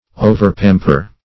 Search Result for " overpamper" : The Collaborative International Dictionary of English v.0.48: Overpamper \O`ver*pam"per\, v. t. To pamper excessively; to feed or dress too much.